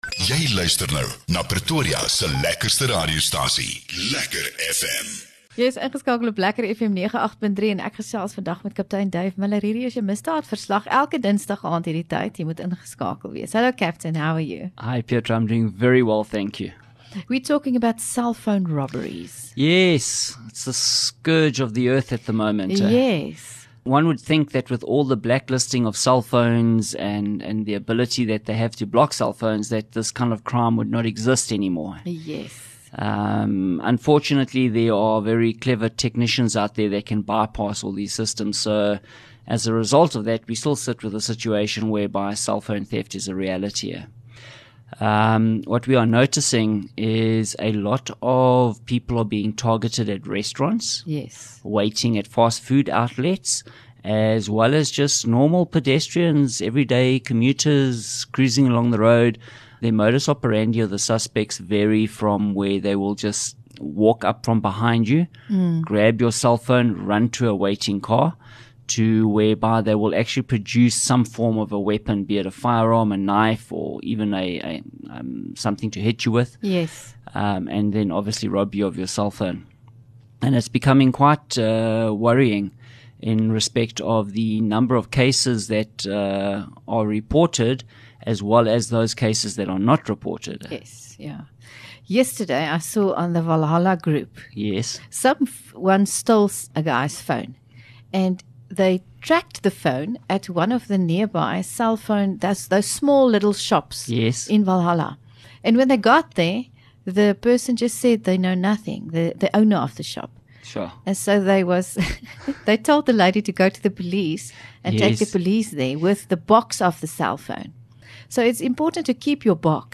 LEKKER FM | Onderhoude 13 Jun Misdaadverslag